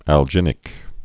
(ăl-jĭnĭk)